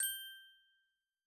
cartoon_wink_magic_sparkle